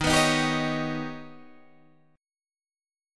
D#+7 chord